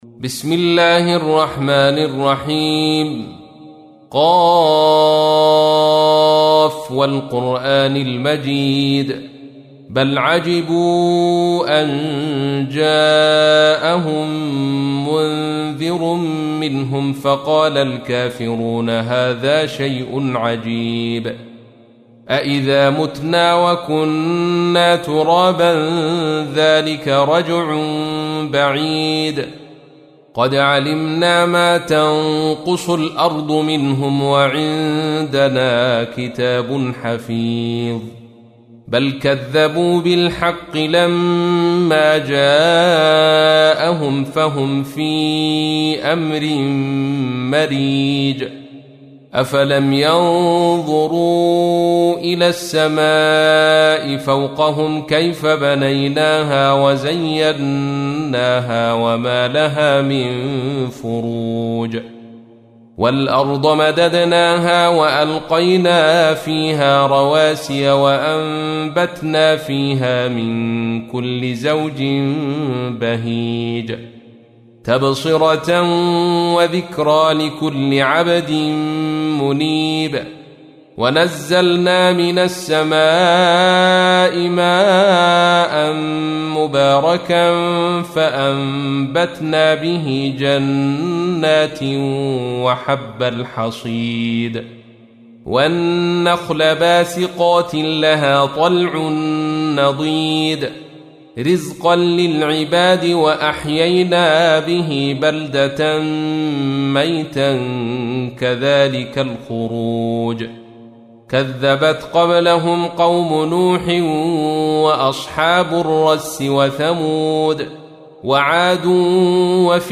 تحميل : 50. سورة ق / القارئ عبد الرشيد صوفي / القرآن الكريم / موقع يا حسين